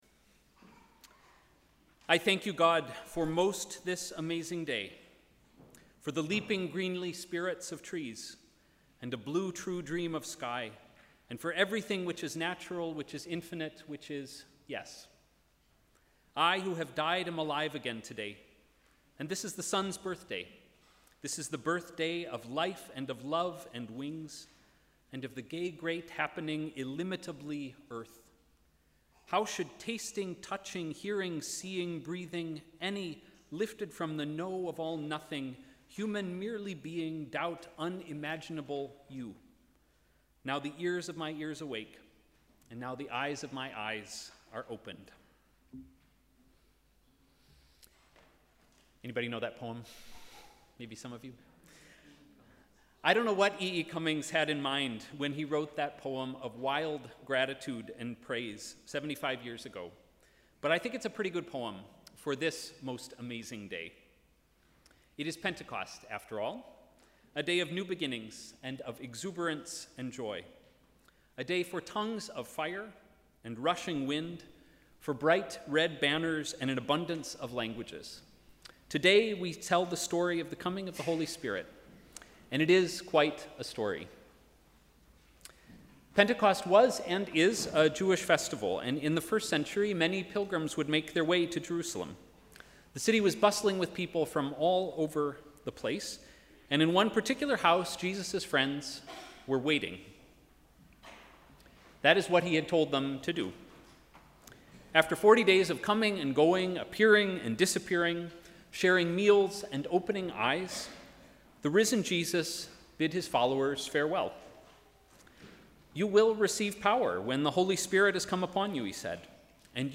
Sermon: ‘This amazing day’
+ Gospel reading in languages read by the confirmands.